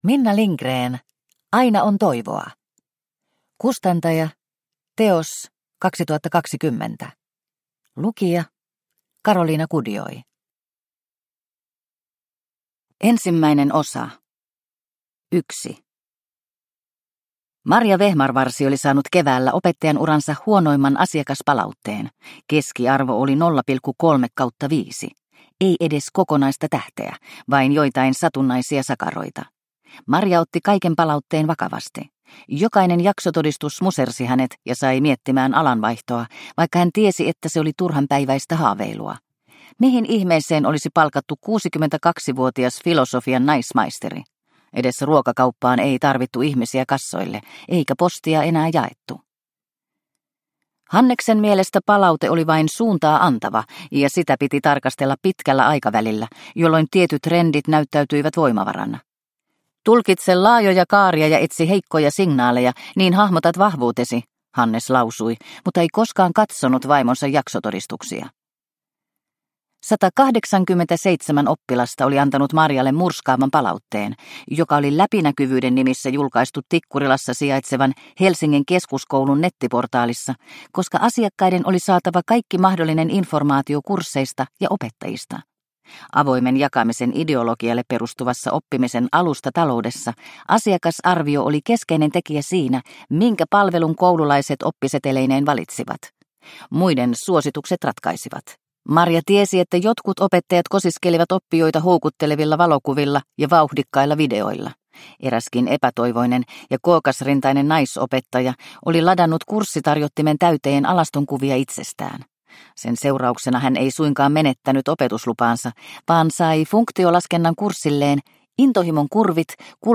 Aina on toivoa – Ljudbok – Laddas ner